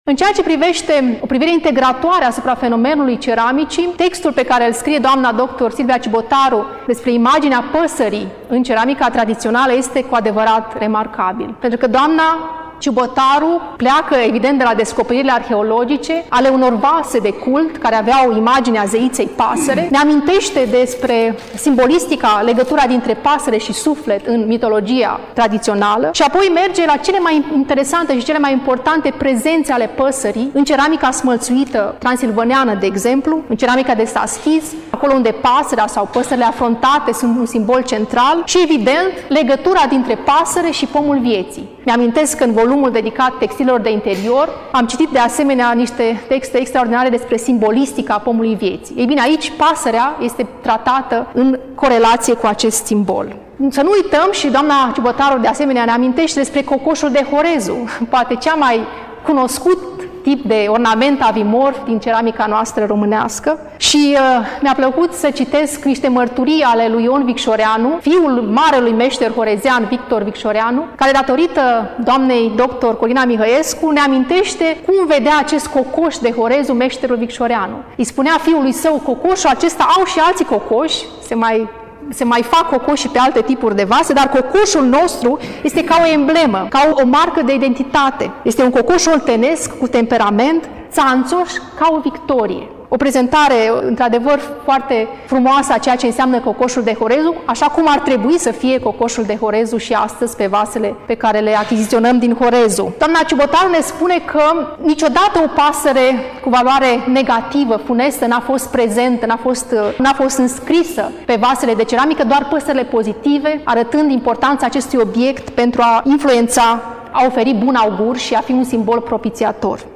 Până la final, difuzăm discursul